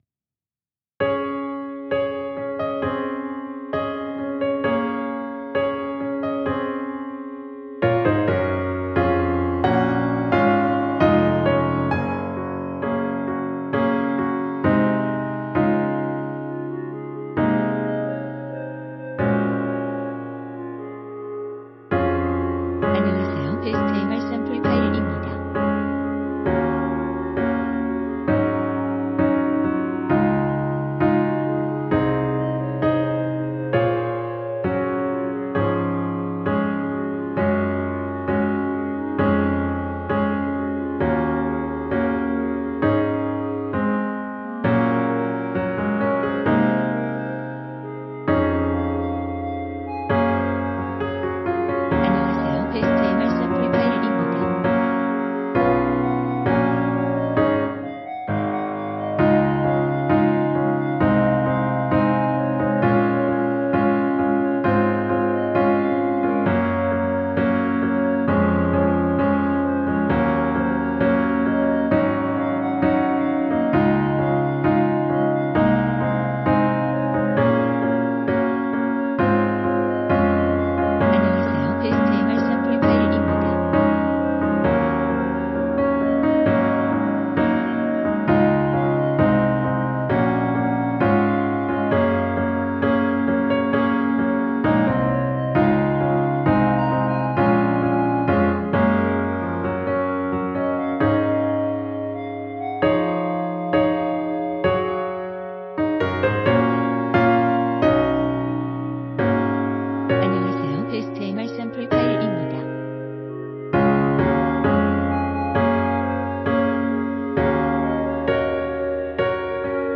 반주가 피아노 하나만으로 되어 있습니다.(미리듣기 확인)
원키 피아노 버전 멜로디 포함된 MR입니다.
Db
앞부분30초, 뒷부분30초씩 편집해서 올려 드리고 있습니다.